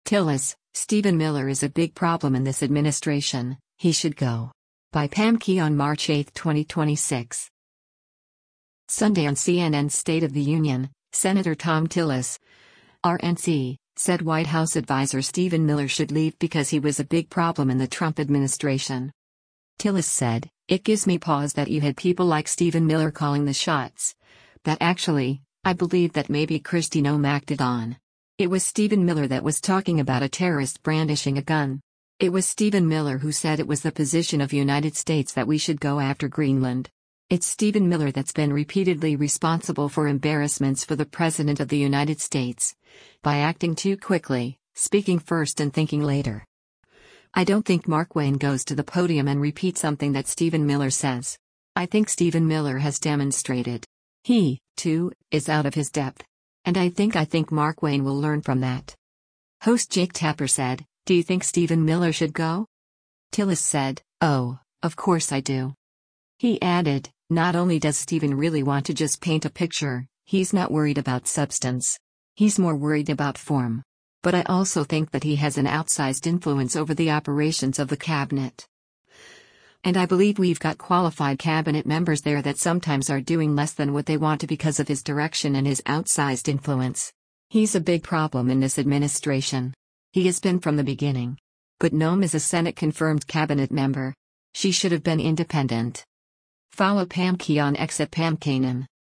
Sunday on CNN’s “State of the Union,” Sen. Thom Tillis (R-NC) said White House adviser Stephen Miller should leave because he was a “big problem” in the Trump administration.